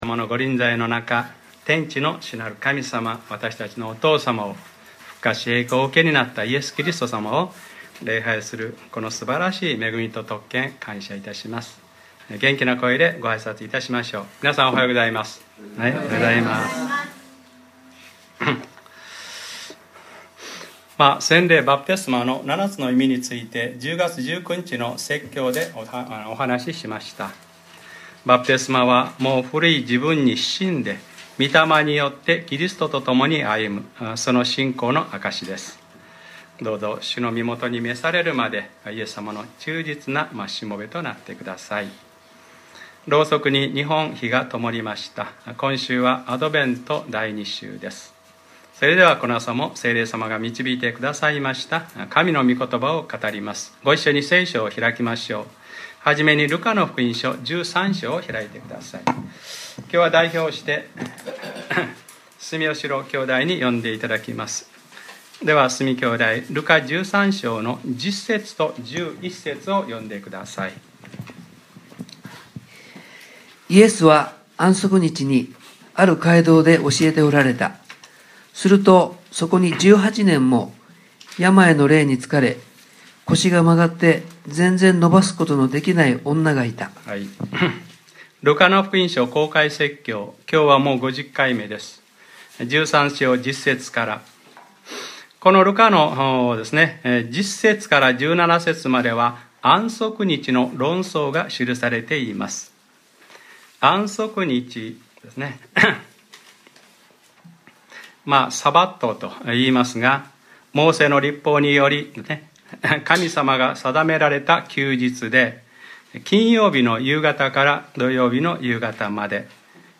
2014年12月07日）礼拝説教 『ルカｰ５０：安息だからといってこの束縛を』